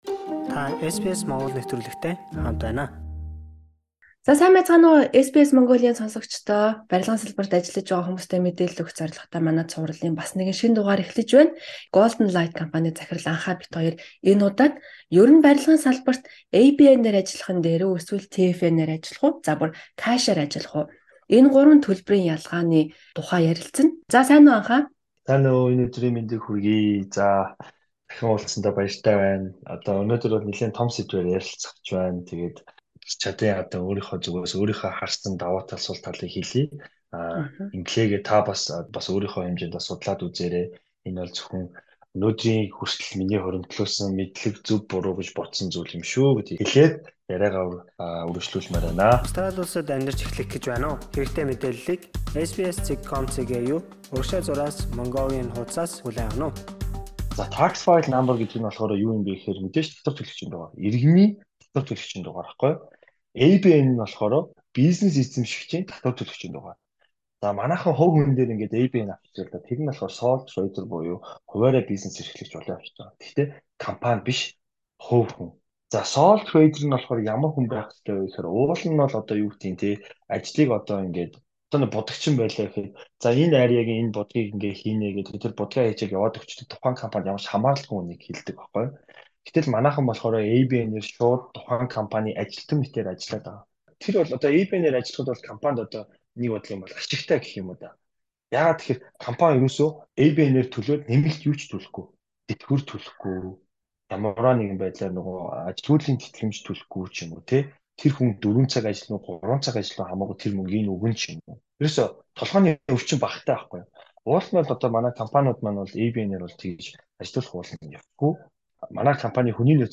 Барилгын салбарын тухай хийж буй цуврал ярилцлагын Гурав дахь дугаараар бид ABN, TFN мөн бэлэн мөнгөөр цалингаа авахын ялгаа болон давуу талуудыг тайлбарлан ярилцлаа.